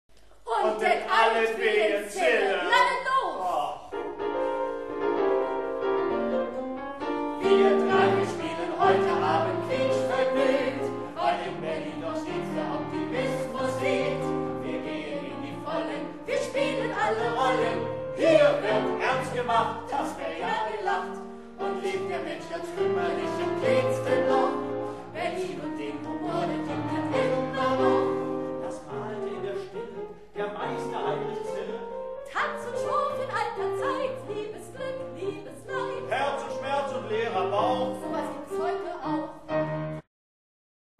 3-Personen-Musical mit Klavierbegleitung